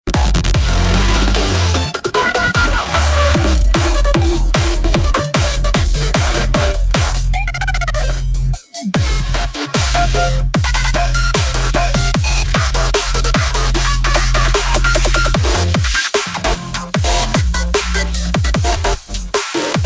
This is like hardstyle got a bit too personal with drum and base.